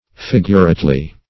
figurately - definition of figurately - synonyms, pronunciation, spelling from Free Dictionary Search Result for " figurately" : The Collaborative International Dictionary of English v.0.48: Figurately \Fig"ur*ate*ly\, adv. In a figurate manner.
figurately.mp3